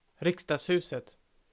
The Parliament House (Swedish: Riksdagshuset [ˈrɪ̂ksdɑː(ɡ)sˌhʉːsɛt]